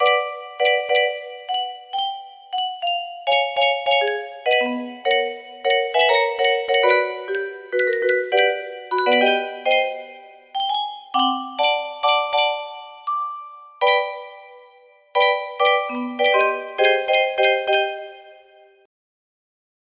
Specially adapted for 30 notes